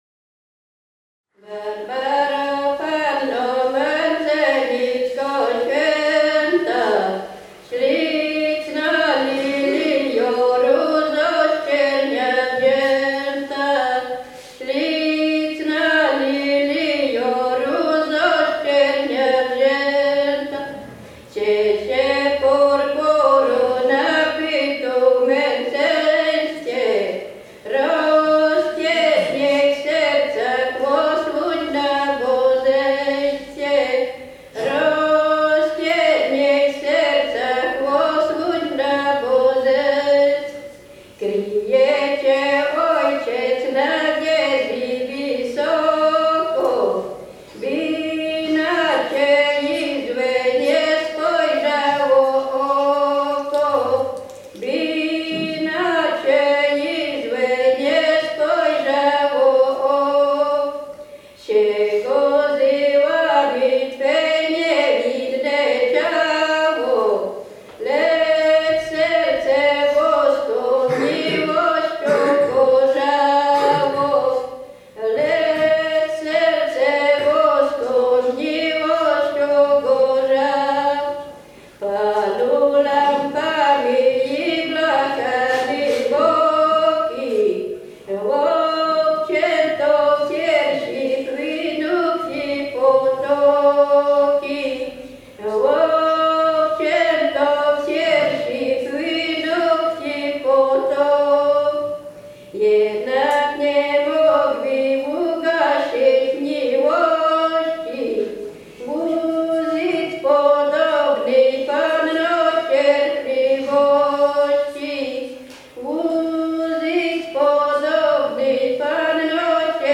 Kurpie
Pieśni o Świętych
nabożne katolickie o świętych